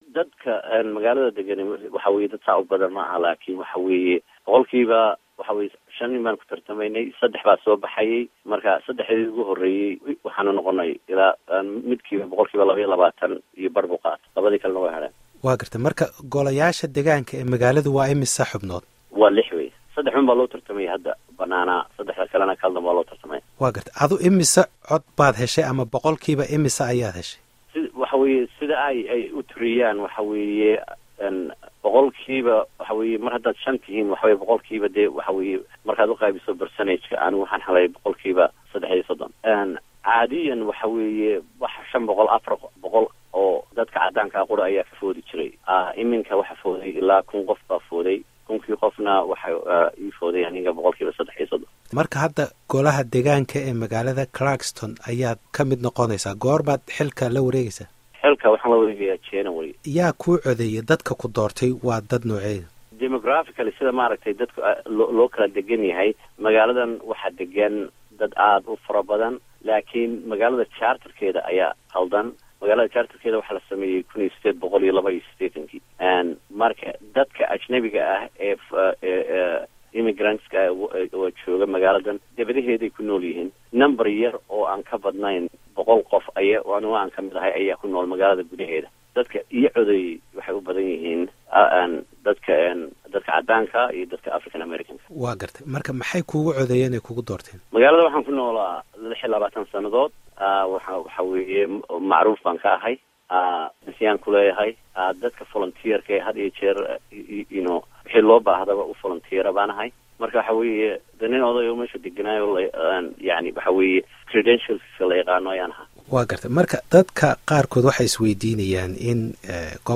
Waraysiga